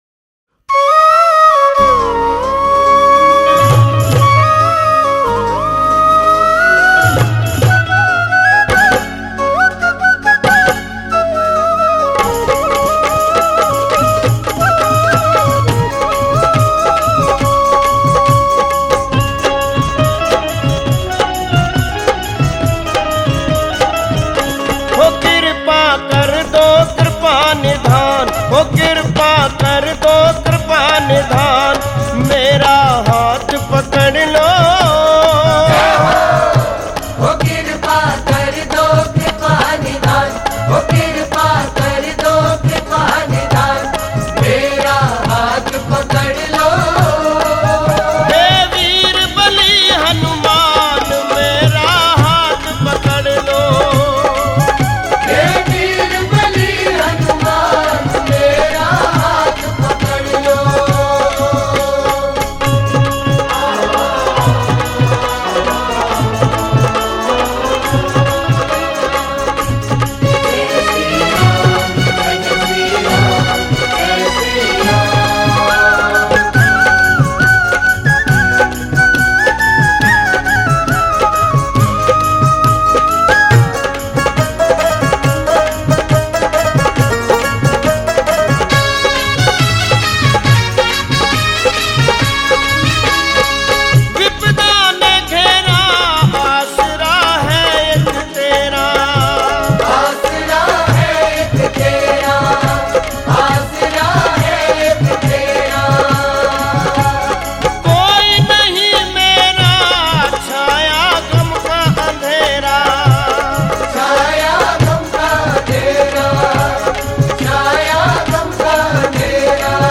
Hanuman Bhajan
Hindi Bhajan